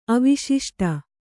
♪ aviśiṣṭa